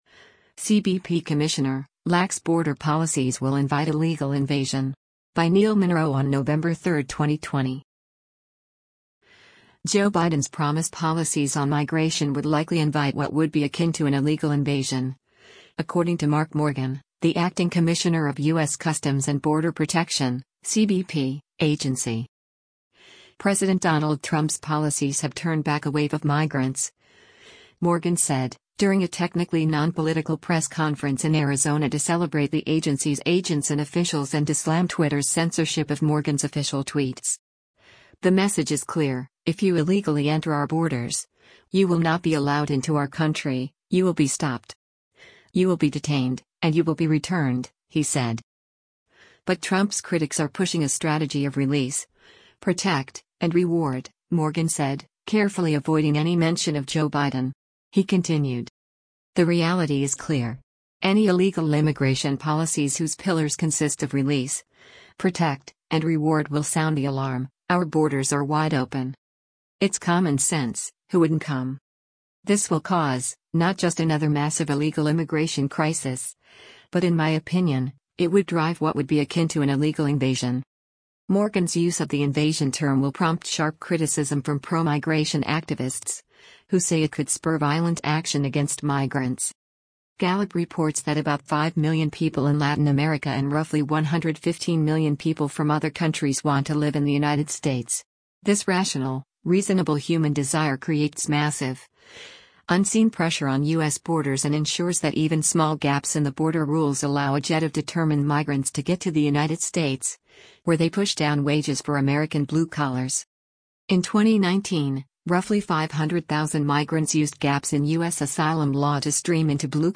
President Donald Trump’s policies have turned back a wave of migrants, Morgan said, during a technically non-political press conference in Arizona to celebrate the agency’s agents and officials and to slam Twitter’s censorship of Morgan’s official tweets.